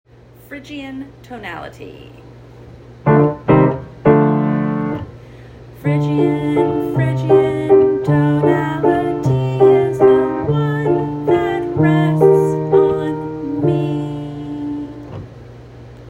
0:16 Aeolian tonality 0:13 Dorian tonality 0:16 Harmonic Minor tonality 0:17 Locrian tonality 0:13 Lydian tonality 0:11 Major tonality 0:12 Minor tonality 0:13 Mixolydian tonality 0:16 Phrygian tonality
Phrygian+tonality.m4a